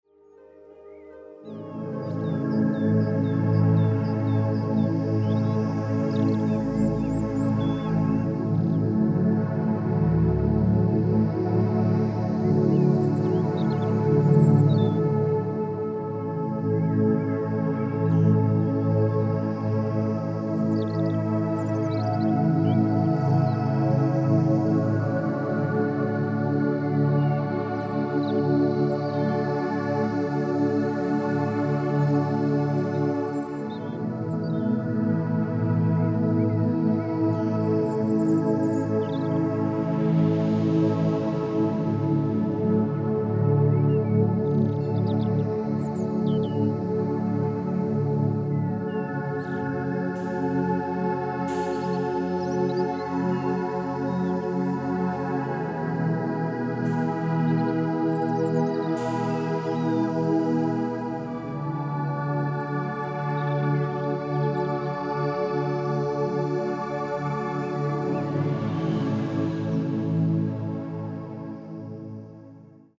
Reiner Klang   11:59 min